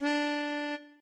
melodica_d.ogg